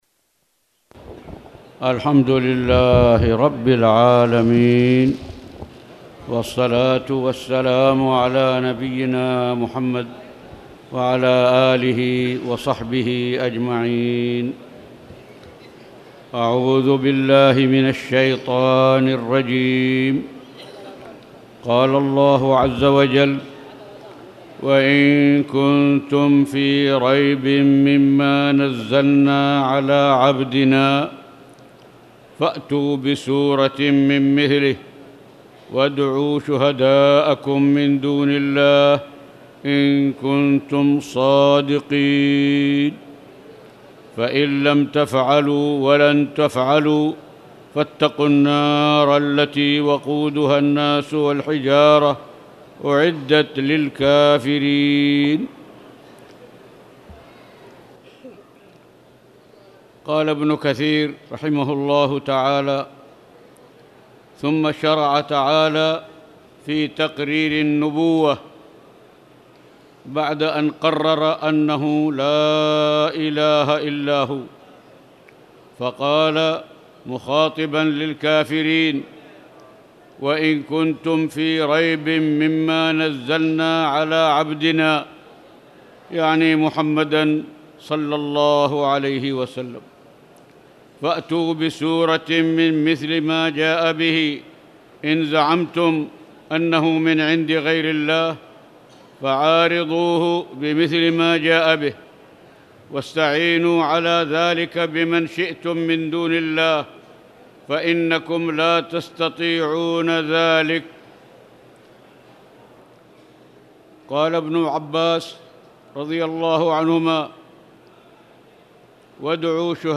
تاريخ النشر ٢١ ذو الحجة ١٤٣٧ هـ المكان: المسجد الحرام الشيخ